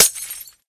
glass1.mp3